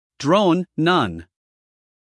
英音/ drəʊn / 美音/ droʊn /